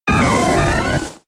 Cri d'Empiflor K.O. dans Pokémon X et Y.